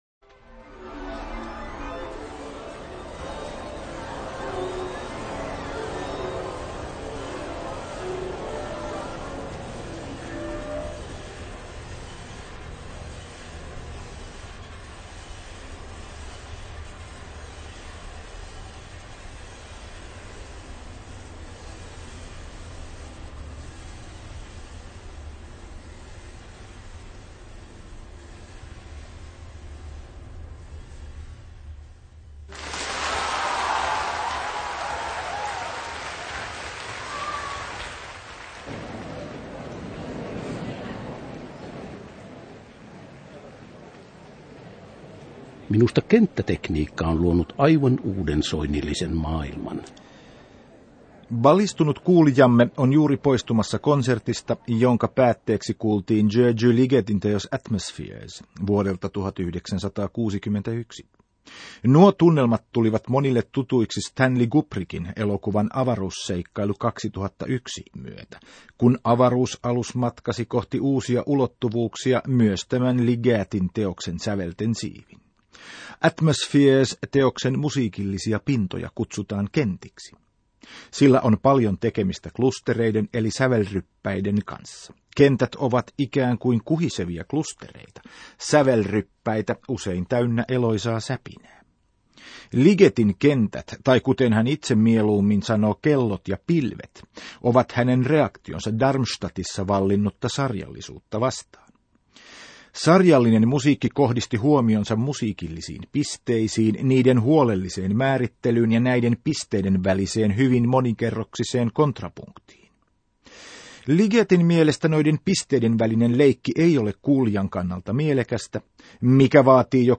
Julkaistu ensi kerran 9.11.2001; Radio Ylen Ykkösen musiikkiohjelmat